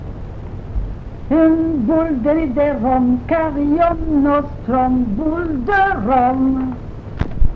Genre : chant
Type : comptine, formulette
Lieu d'enregistrement : Jolimont
Support : bande magnétique
Comptine, imitation du latin.